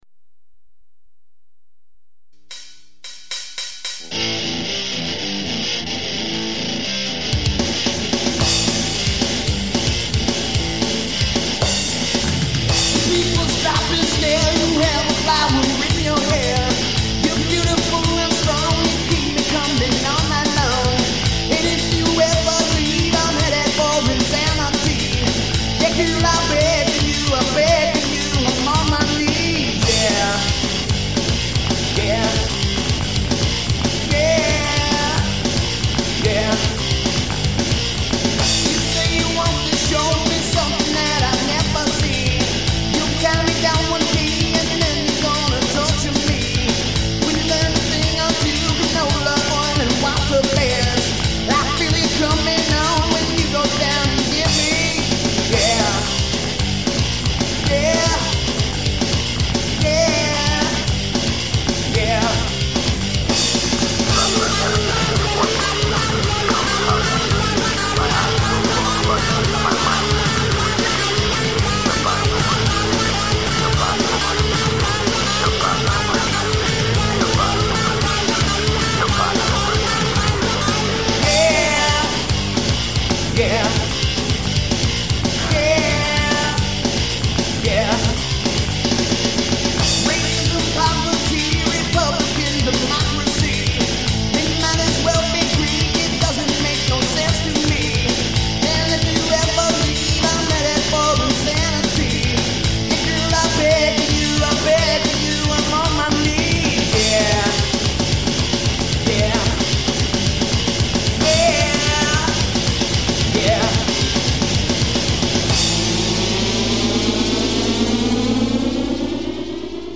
Hard hitting, rythmic mayhem with a hook!